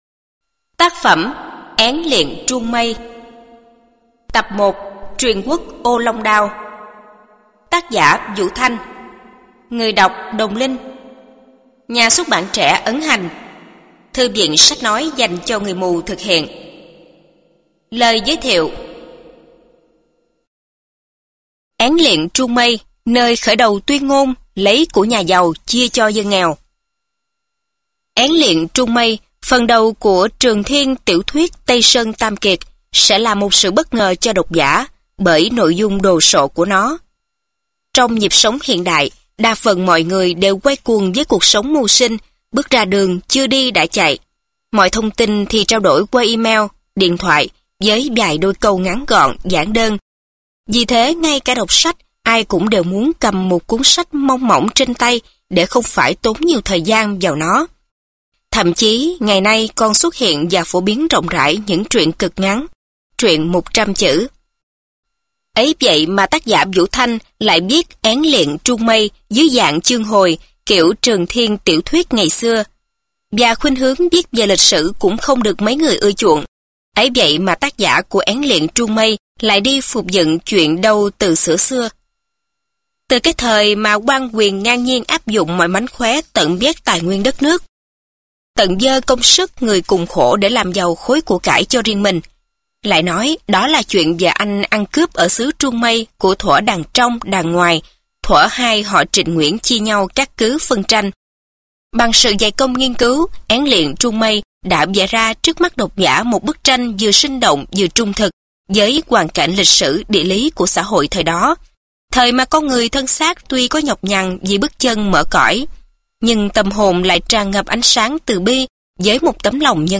Sách nói Én Liệng Truông Mây 1 - Vũ Thanh - Sách Nói Online Hay